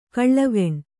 ♪ kaḷḷaveṇ